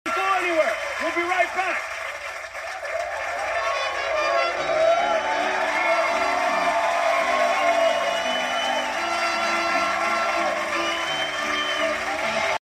Carlos Mencia blows a horn and Mexican music comes out